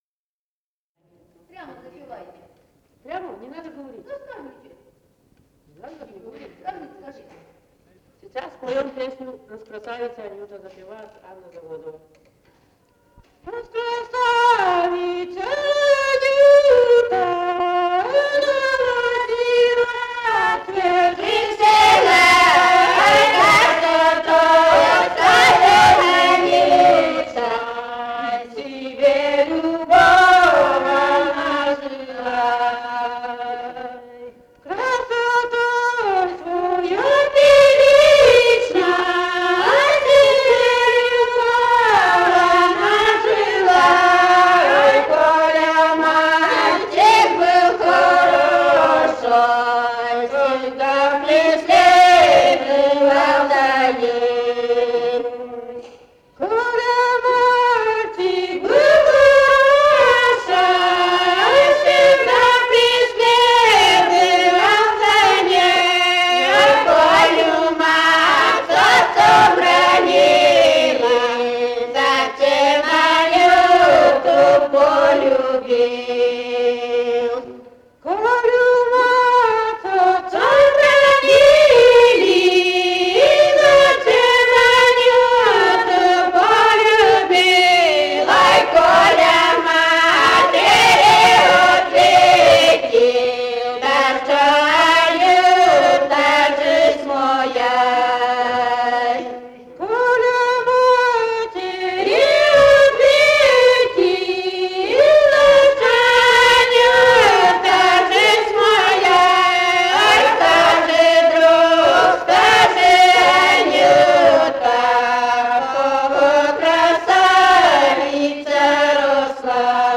«Раскрасавица Анюта» (лирическая).